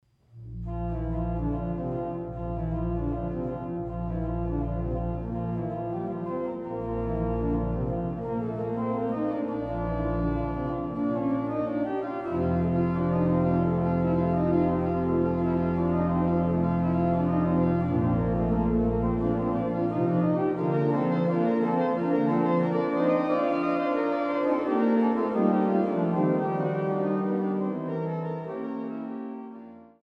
Stadtkirche St. Petri zu Löbejün